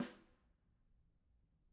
Snare2-HitSN_v3_rr1_Sum.wav